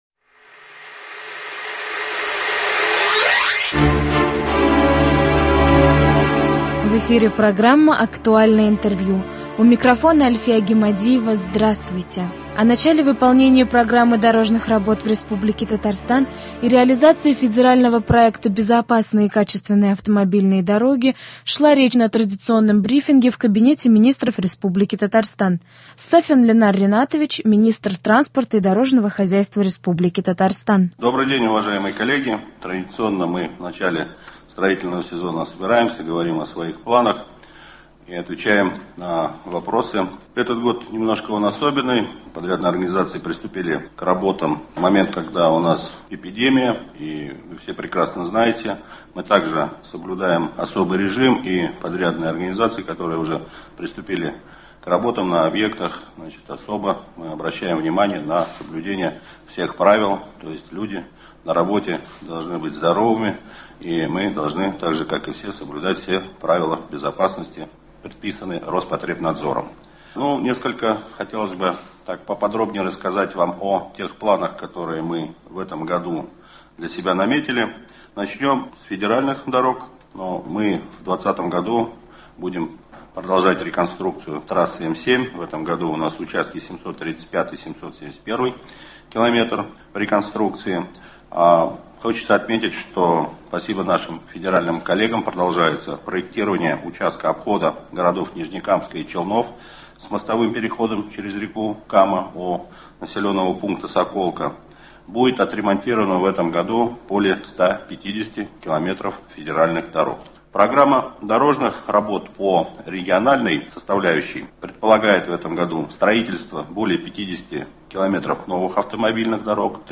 Актуальное интервью. 29 апреля.